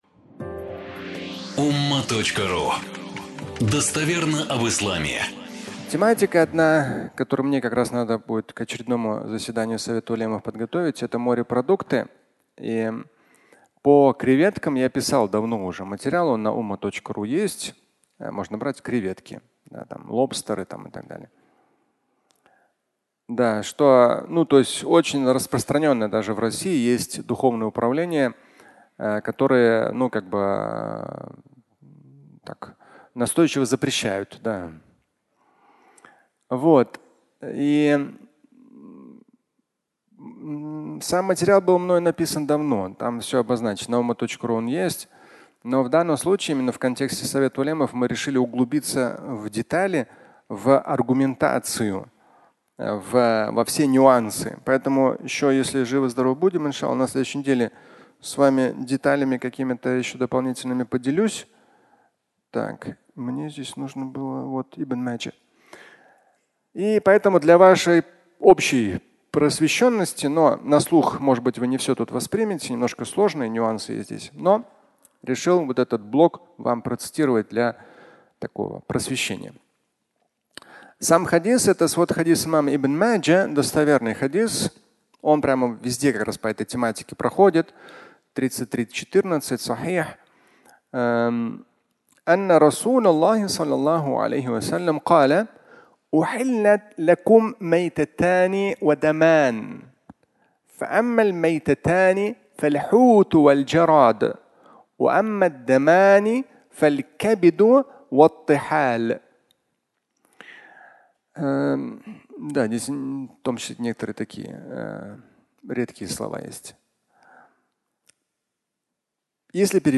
Креветки (аудиолекция)